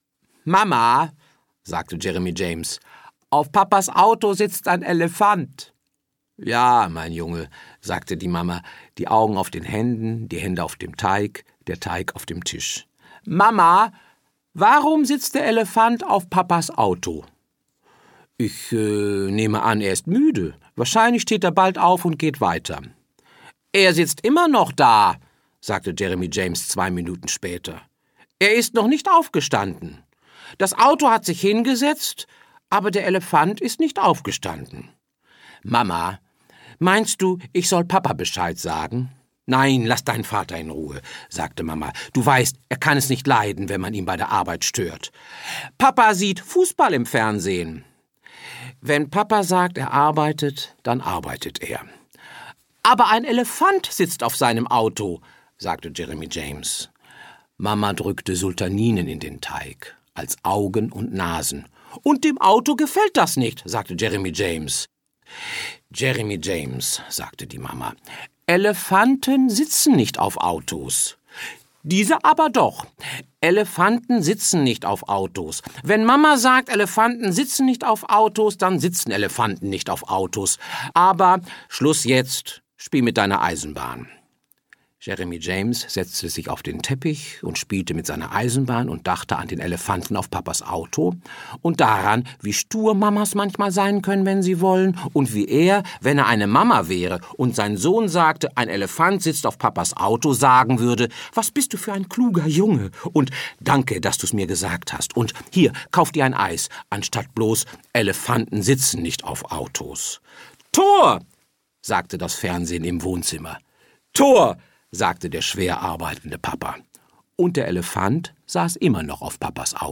Hermann Lause (Sprecher)